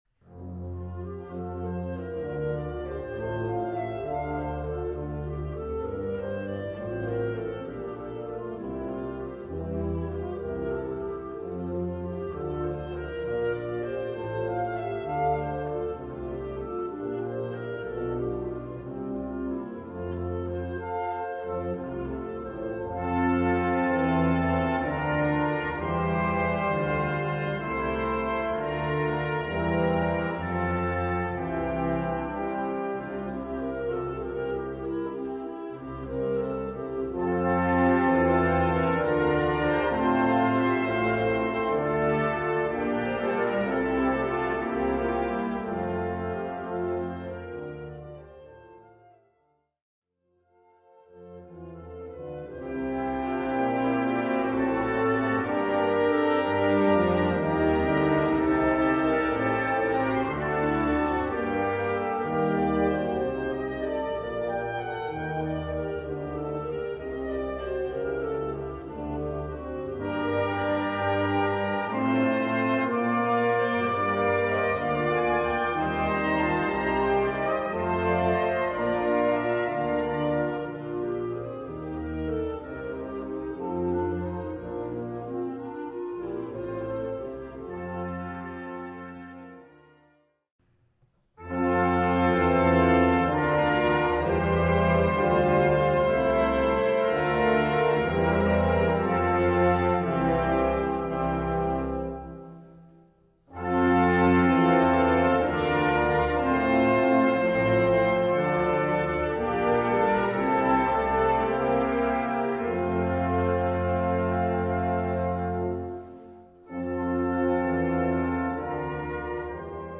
Gattung: Choräle für Blasorchester
Besetzung: Blasorchester